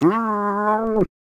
sounds / monsters / cat